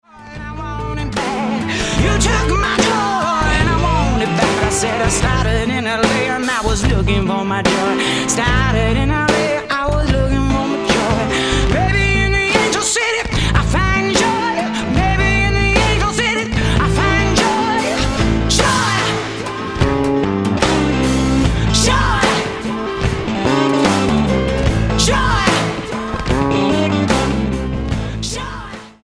STYLE: Blues